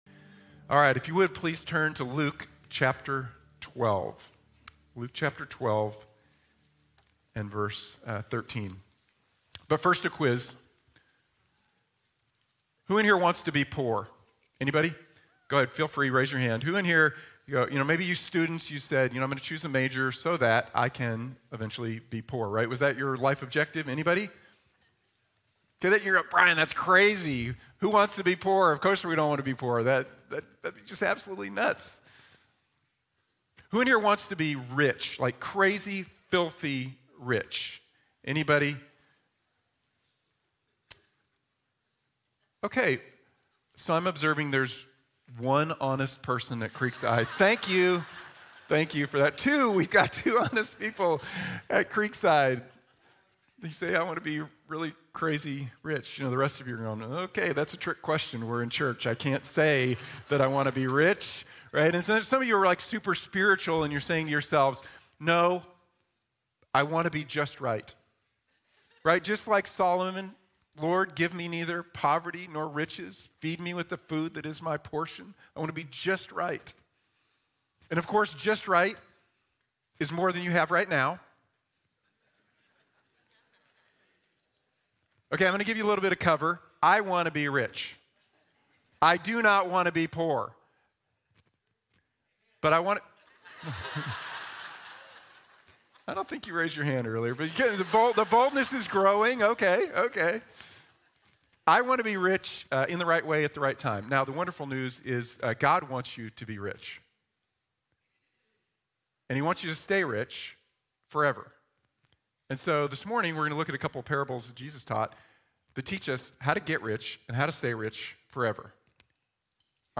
Invest in Eternity | Sermon | Grace Bible Church